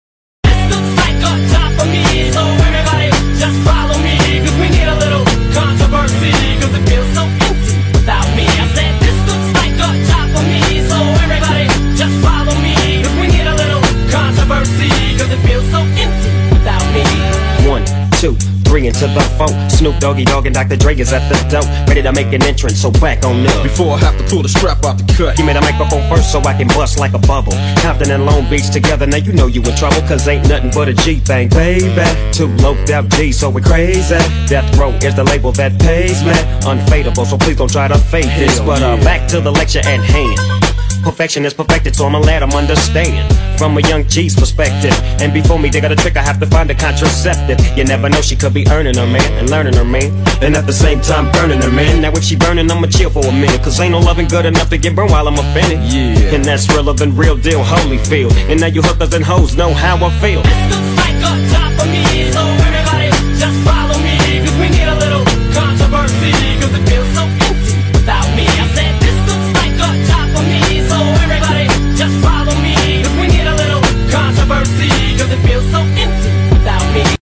ریمیکس اول | مشاپ